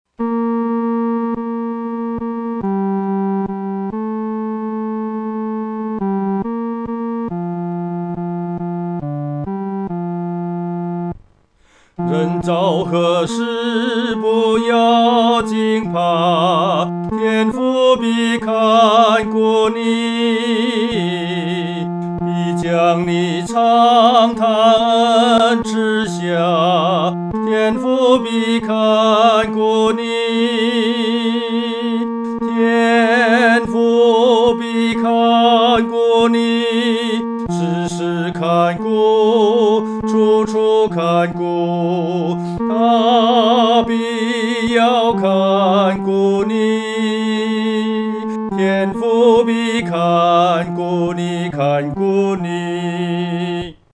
独唱（第三声）
天父必看顾你-独唱（第三声）.mp3